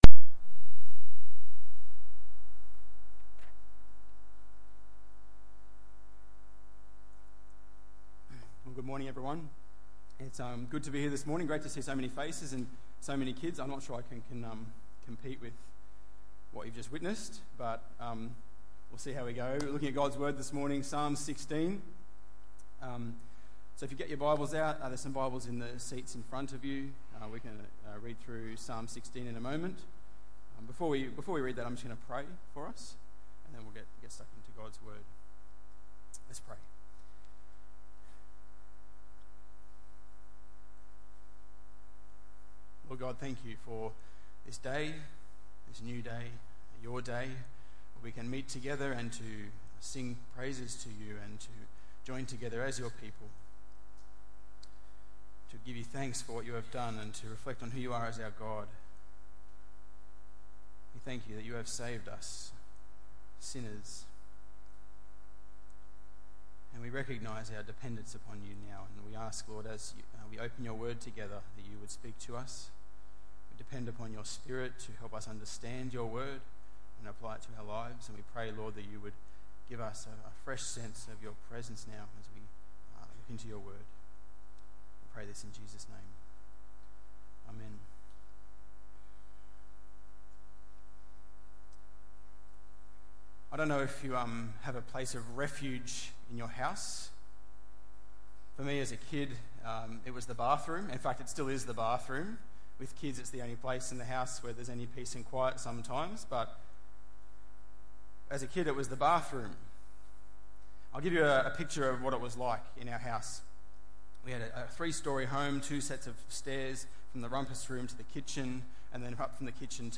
Psalm 16:1-11 Tagged with Sunday Morning